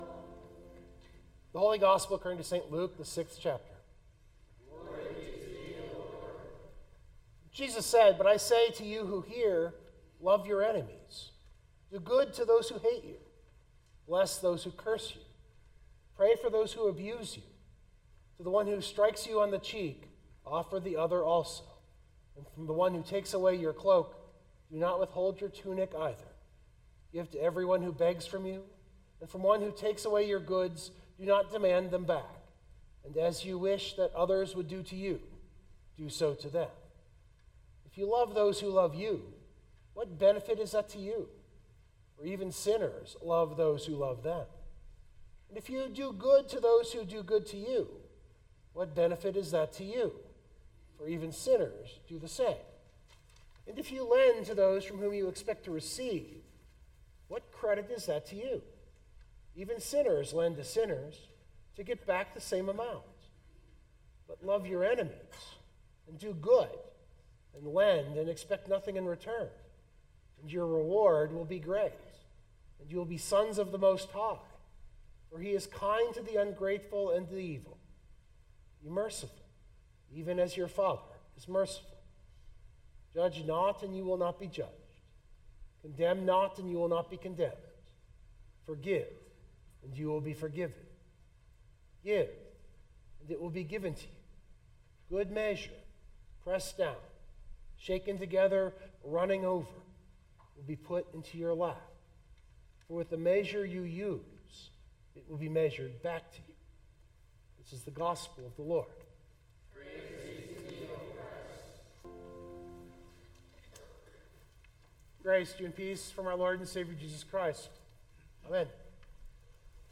022325 Sermon Download Biblical Text: Luke 6:27-38 Luke’s sermon on the plain – this text is the 2nd part of that – is his version of Matthew’s Sermon on the Mount.